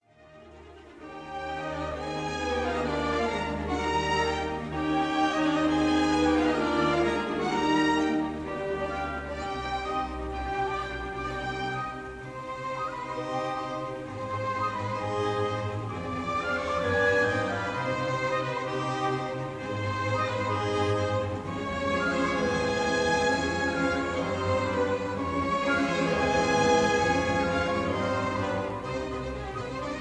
conductor